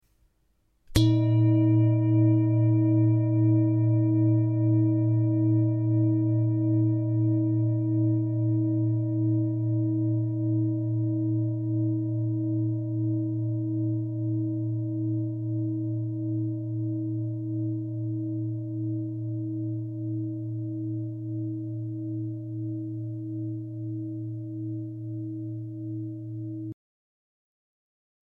Indische Bengalen Klangschale - CERES
Grundton: 118,43 Hz
1. Oberton: 317,74 Hz
PLANETENTON CERES TON AIS